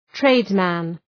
Προφορά
{‘treıdzmən}